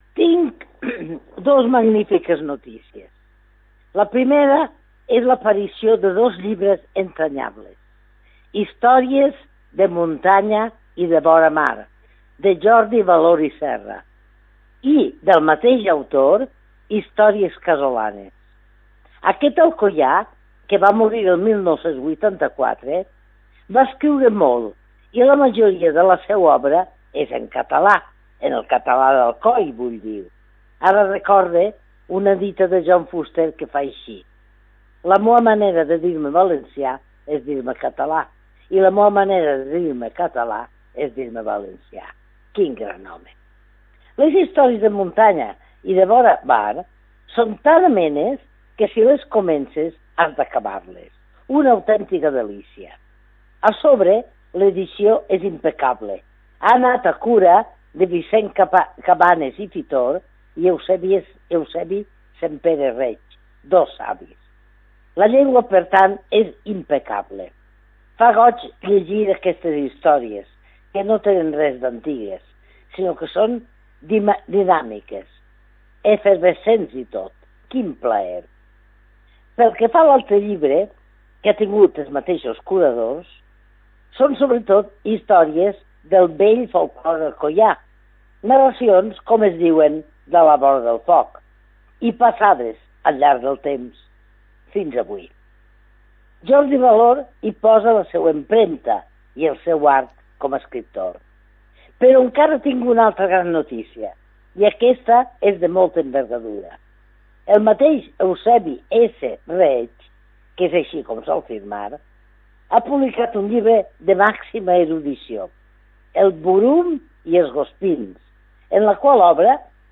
Isabel-Clara Simó és escriptora i filla predilecta d'Alcoi